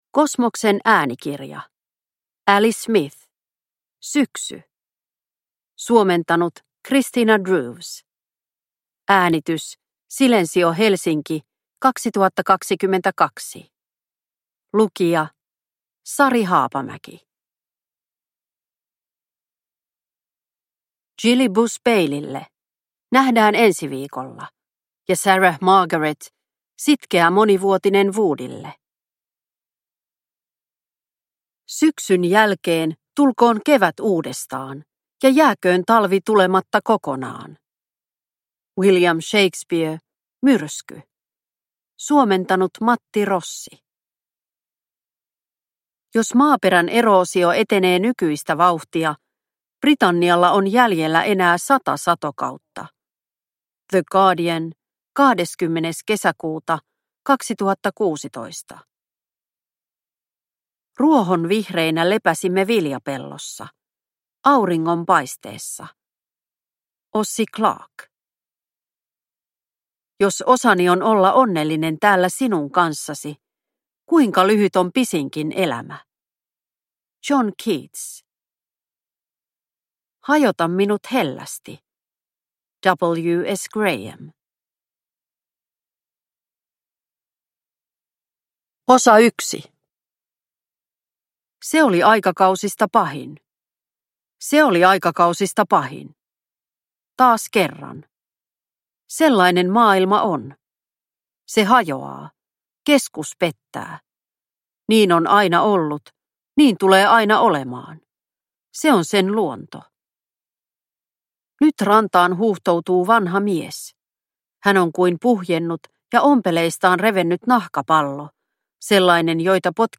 Syksy – Ljudbok – Laddas ner